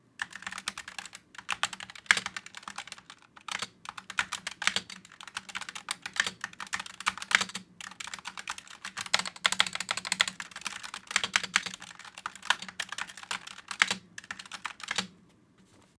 Typing Fast_A01
fast MUS152 Typing sound effect free sound royalty free Memes